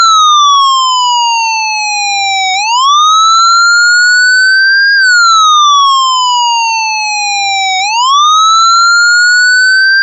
SSPWail.mp3